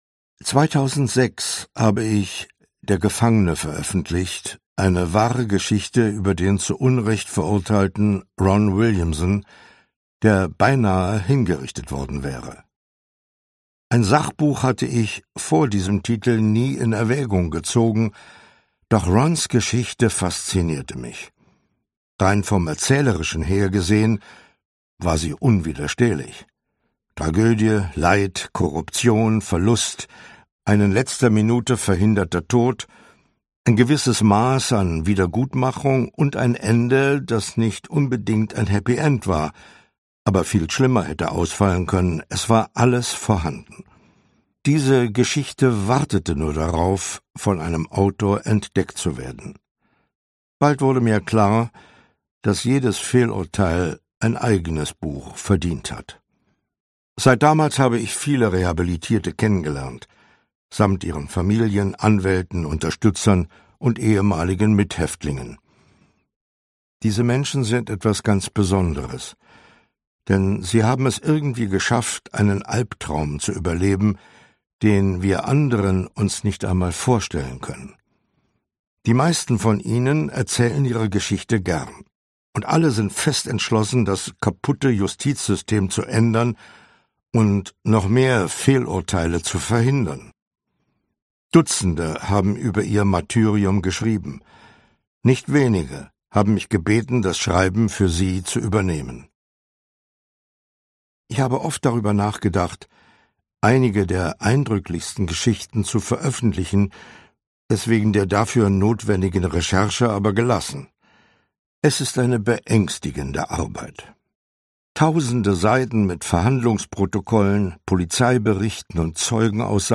Charles Brauer (Sprecher)
leicht gekürzte Lesung mit Charles Brauer